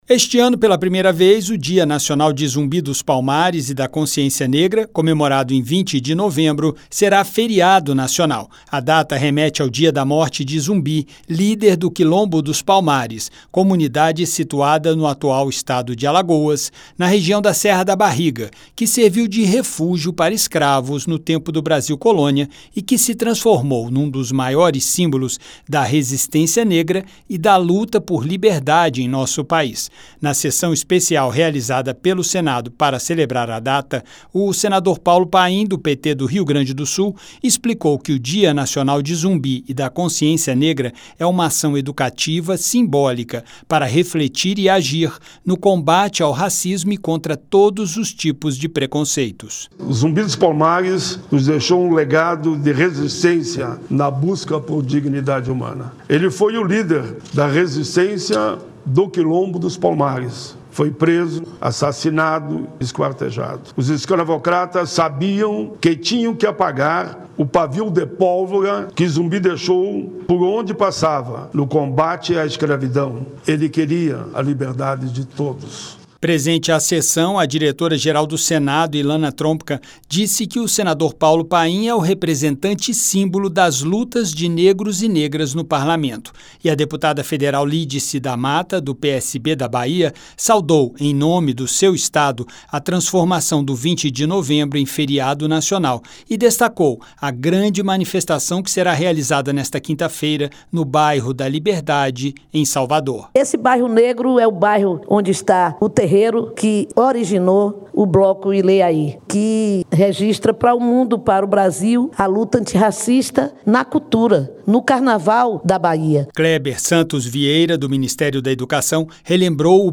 O Senado celebrou nesta terça (19), em sessão especial no Plenário, o Dia Nacional da Consciência Negra, comemorado em 20 de novembro. O senador Paulo Paim (PT-RS) destacou o legado de resistência e busca pela dignidade humana deixado por Zumbi, líder do Quilombo dos Palmares, comunidade que serviu de refúgio para escravizados no Brasil Colônia e que hoje é um símbolo da luta pela liberdade.